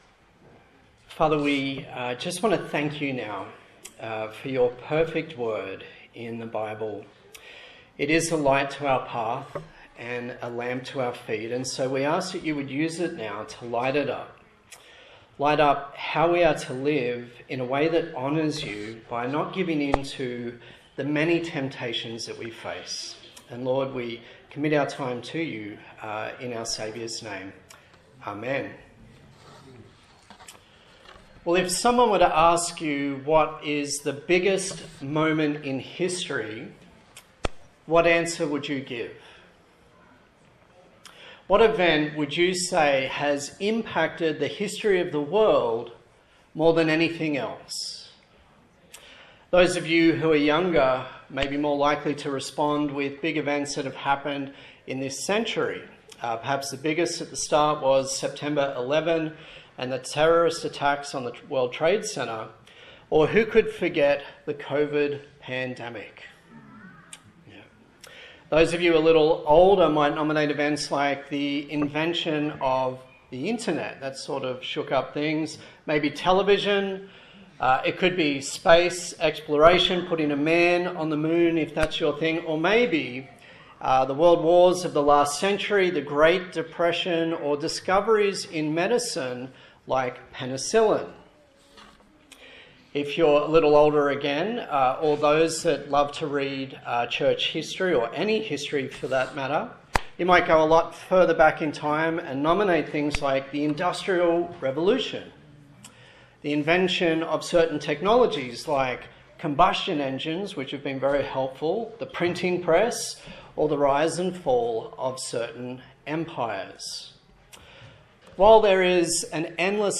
Passage: Matthew 4:1-11 Service Type: Sunday Morning